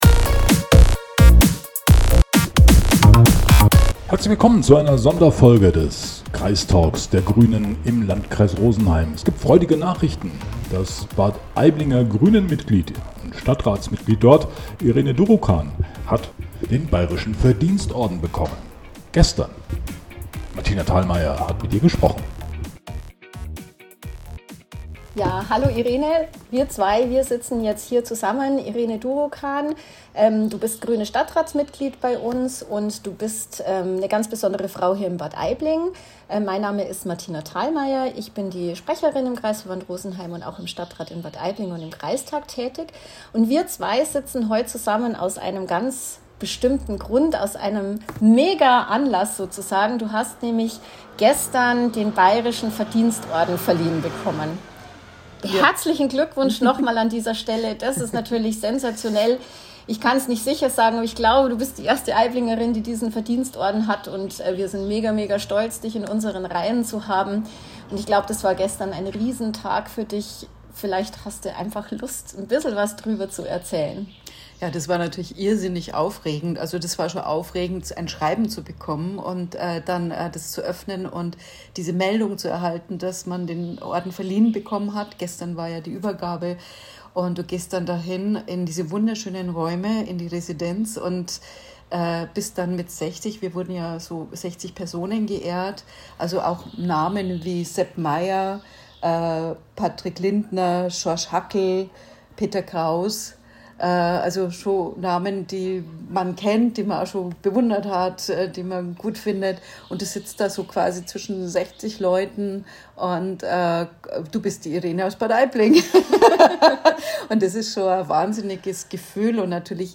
Martina Thalmayr spricht mit ihrer Aiblinger Stadtratskollegin Irene Durukan, nachdem diese am Donnerstag, den 11. Juli 2024, den bayerischen Verdienstorden verliehen bekommen hat.